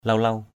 /lau-lau/ (t.) huy hoàng, lộng lẫy = splendide. splendid.